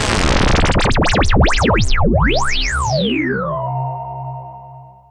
Drop_FX_6_C2.wav